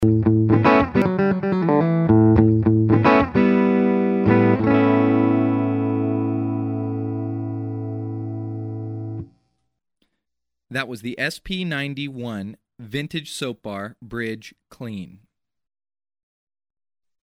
Ses médium boostés lui assurent une place sur les guitares des rockeurs psyché & progressifs.
P90-BridgeClean.mp3